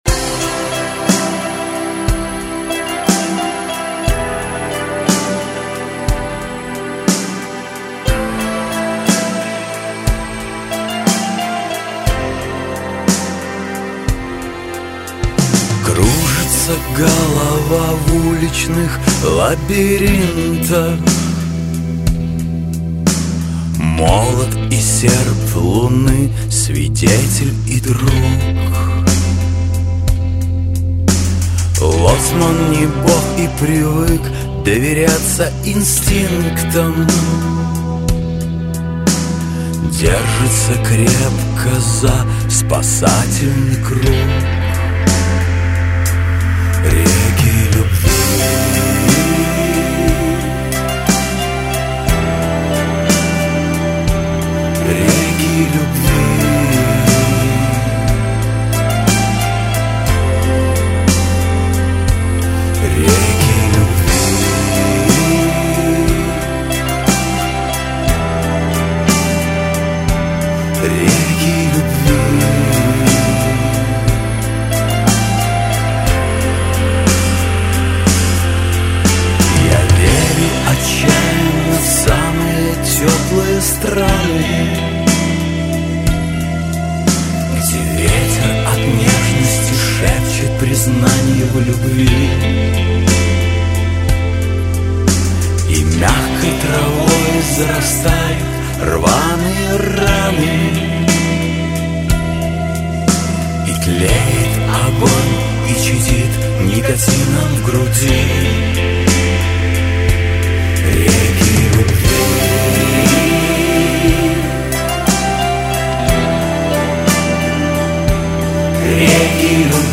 Такт 6/8